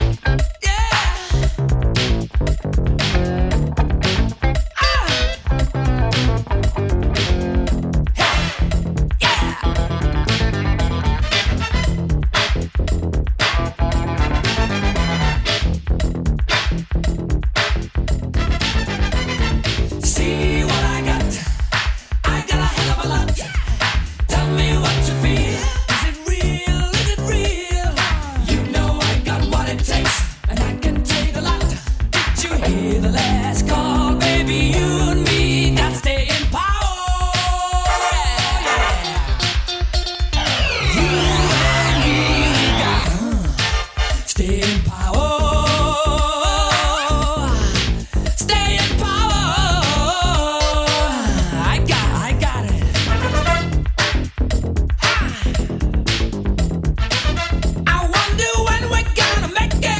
Рок
Упор сделан на танцевальный стиль.